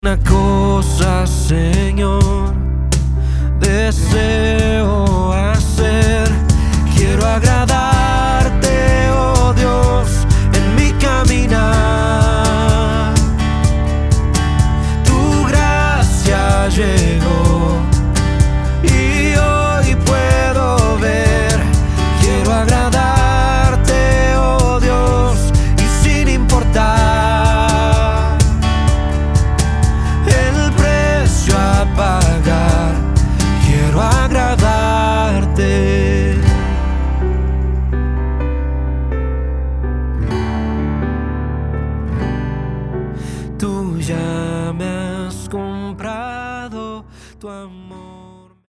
Acordes - G